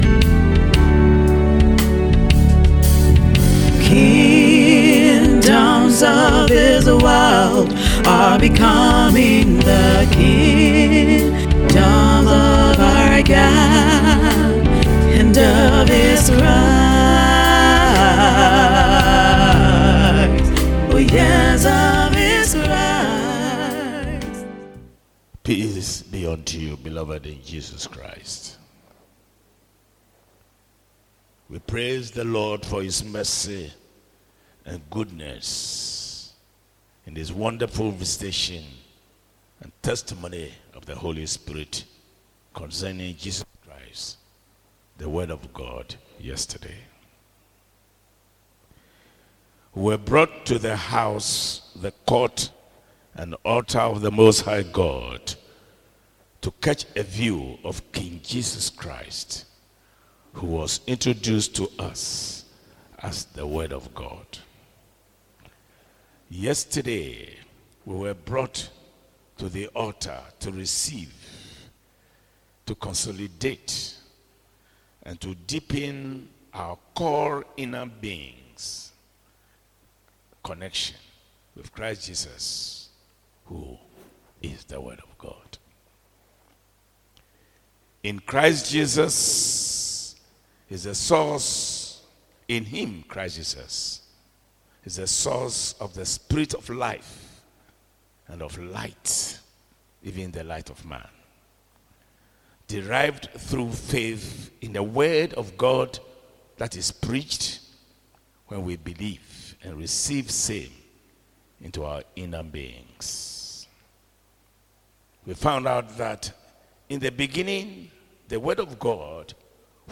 Series: Audio Sermon